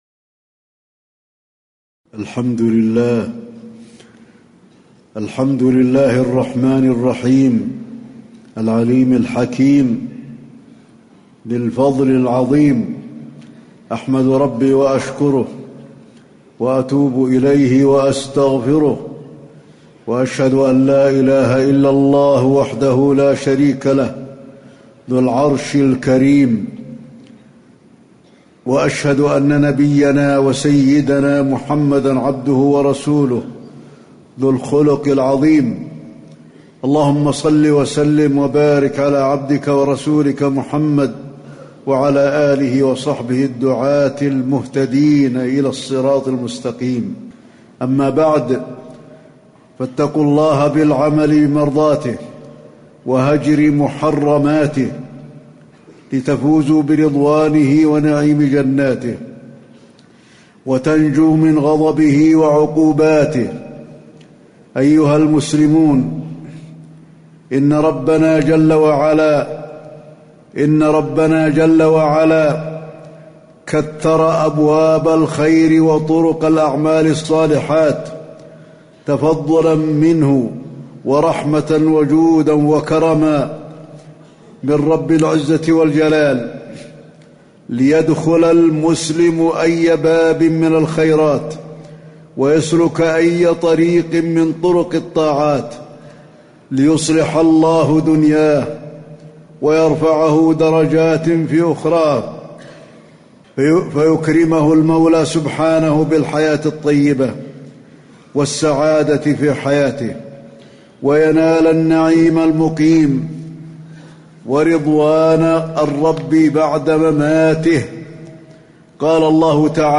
تاريخ النشر ٦ ربيع الأول ١٤٣٩ هـ المكان: المسجد النبوي الشيخ: فضيلة الشيخ د. علي بن عبدالرحمن الحذيفي فضيلة الشيخ د. علي بن عبدالرحمن الحذيفي فضل الاستغفار